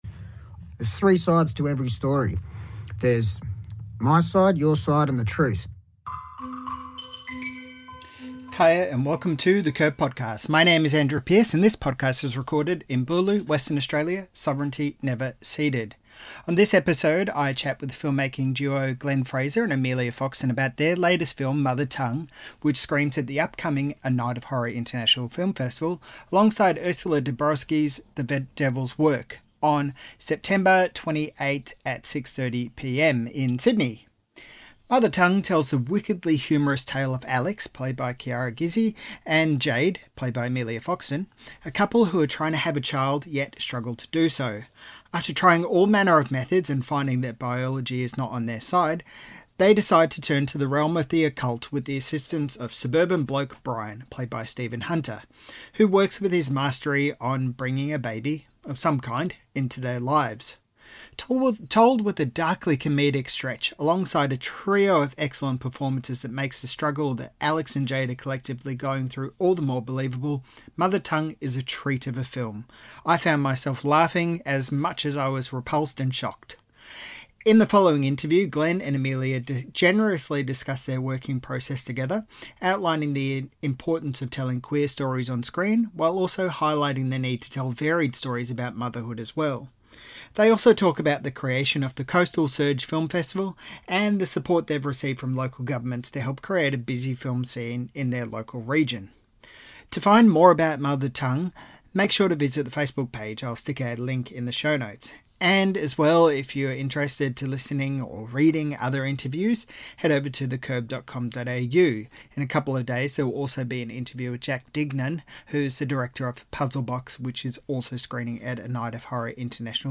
Interview - The Curb